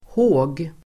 Uttal: [hå:g]